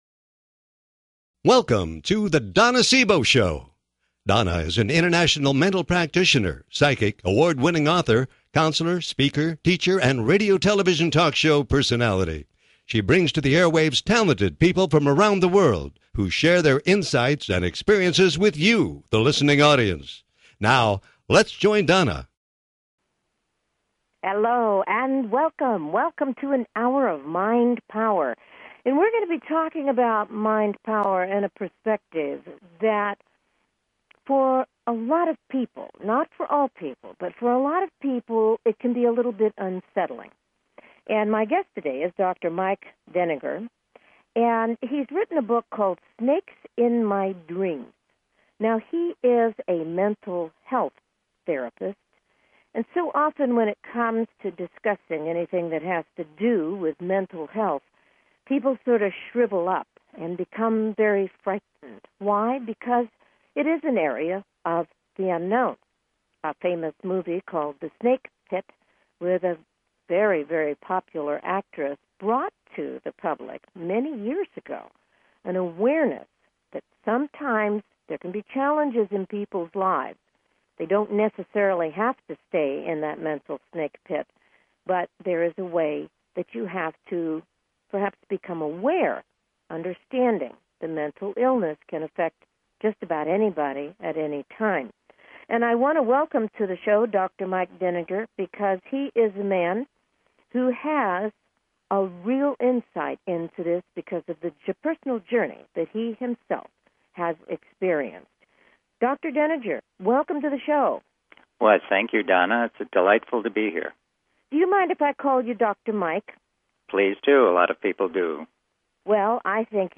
Talk Show Episode
Guests on her programs include CEO's of Fortune 500 companies to working mothers.